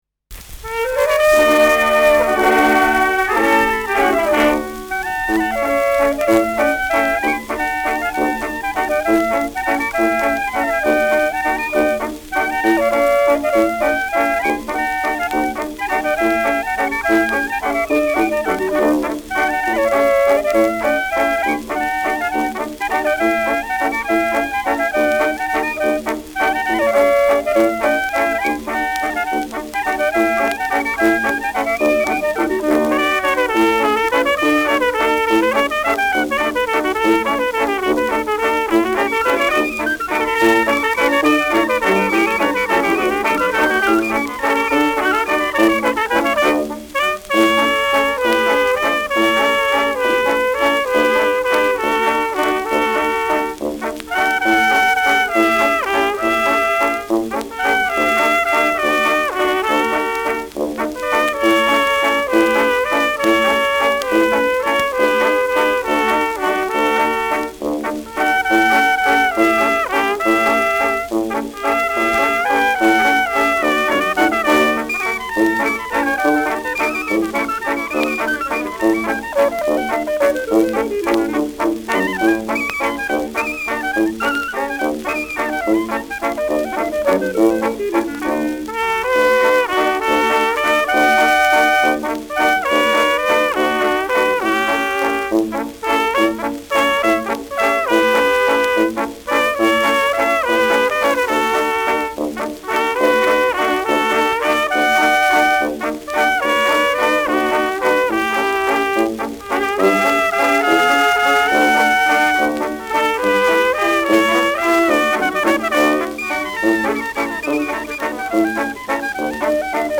Schellackplatte
leichtes Rauschen : leichtes Knistern : leichtes Leiern : vereinzeltes Knacken
Dachauer Bauernkapelle (Interpretation)
[München] (Aufnahmeort)